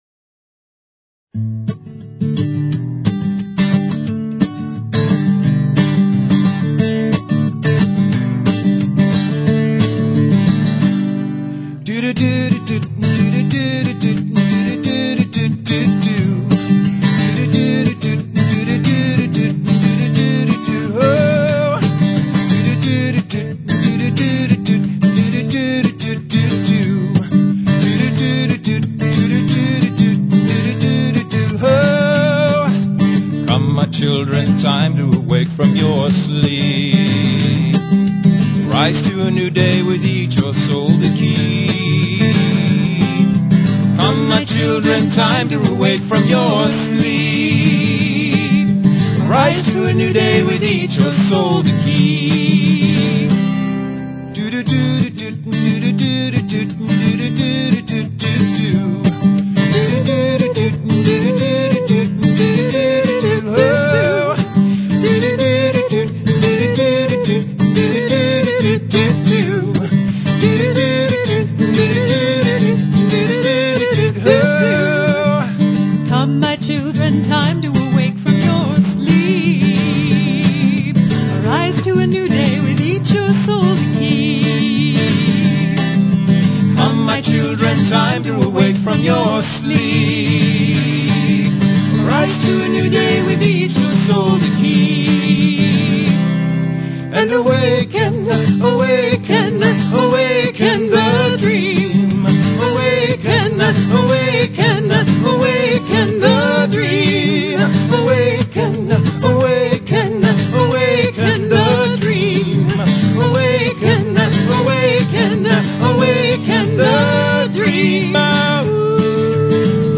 Talk Show Episode, Audio Podcast, Enlightened_Medicine and Courtesy of BBS Radio on , show guests , about , categorized as
The last half of the show will be a Prosperity Blessings Meditation, per request of one of the benefactors of the show (so buckle up & prepare to be Blessed!).